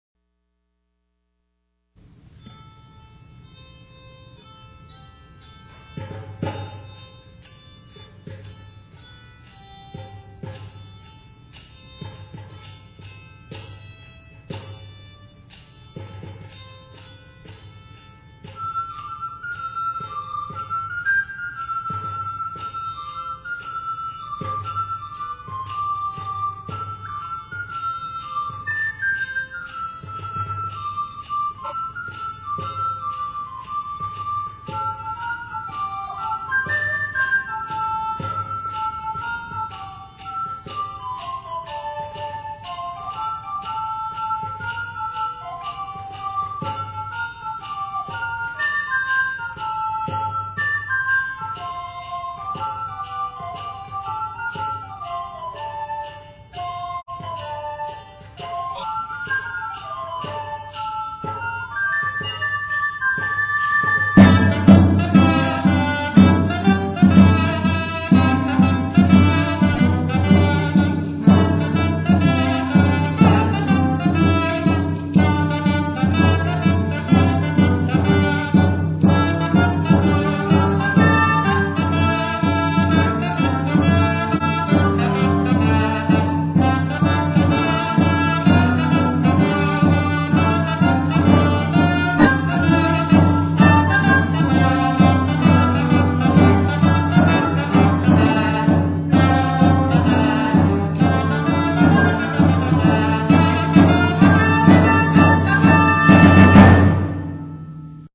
Click to download MP3 recordings of past concerts.